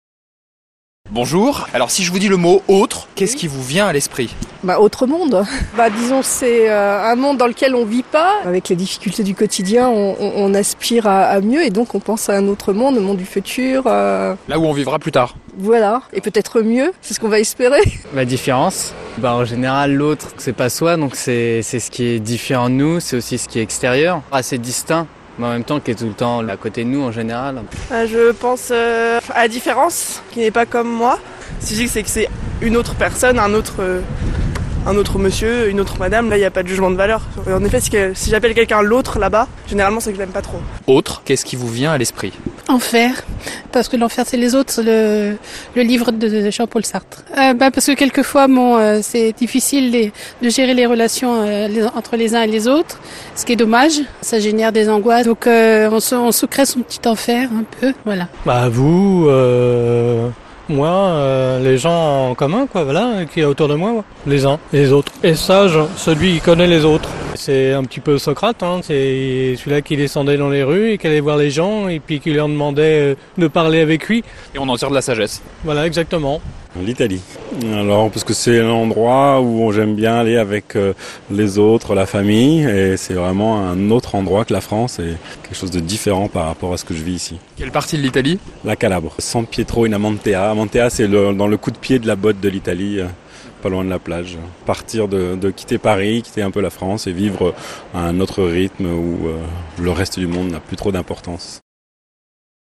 Micro-trottoir_autre.mp3